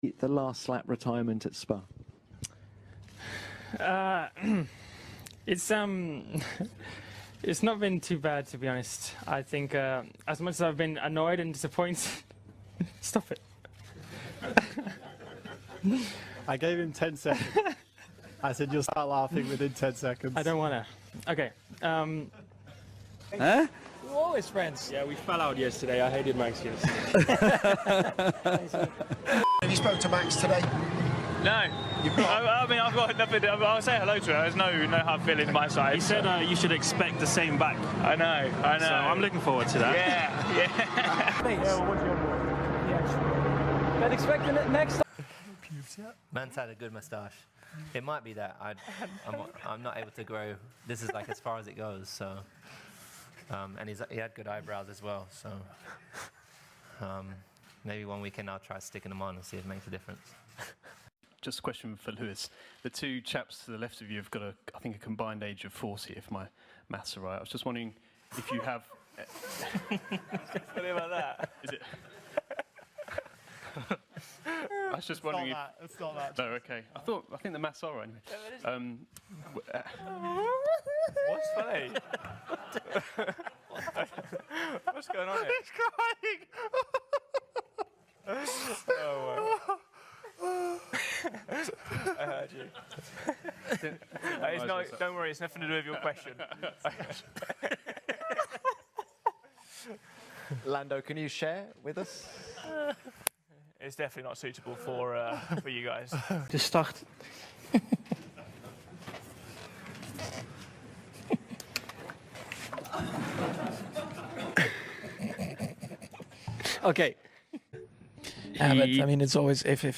F1 Press Conference Moments Sound Effects Free Download